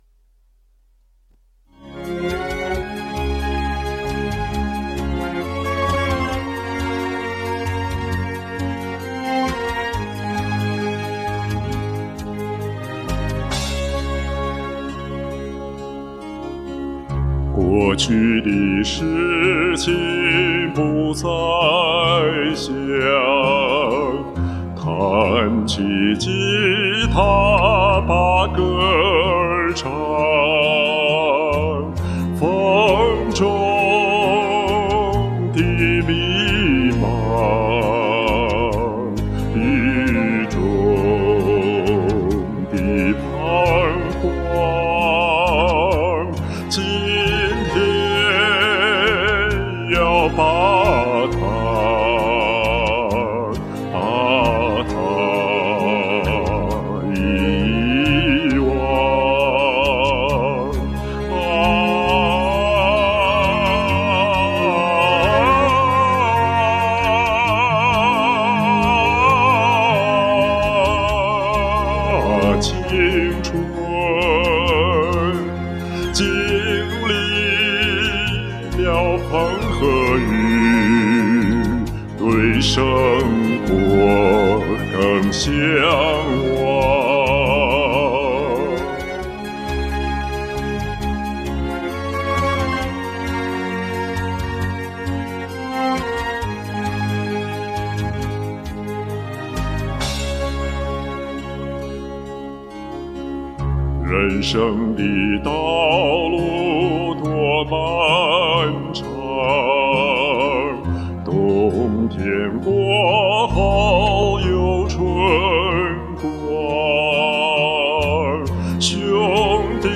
厚重大气， 感情层层推进，太专业了！
第一句里的那个小颤音拐得很顺溜
醇厚中音
优美自然！